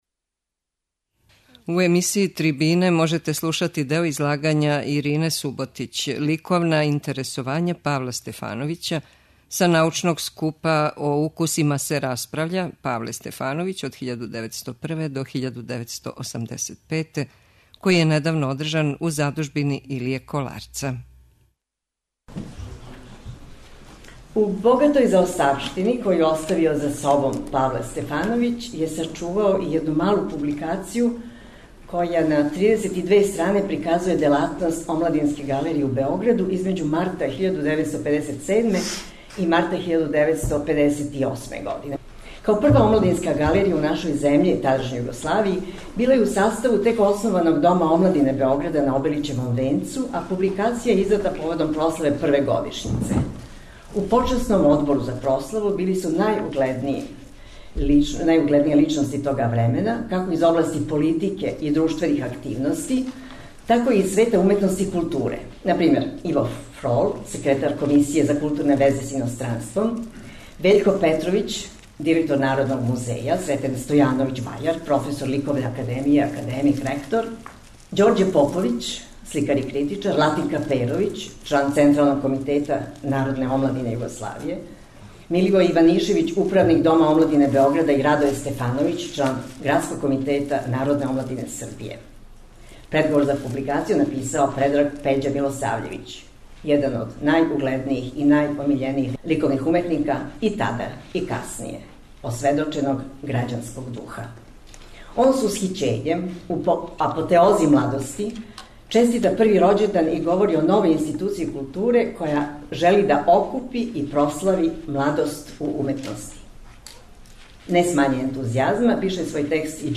Са научног скупа о делу Павла Стефановића, уторком од 14. јуна до 5. јула 2016, преносимо делове излагања о стваралаштву књижевника, есејисте и критичара Павла Стефановића.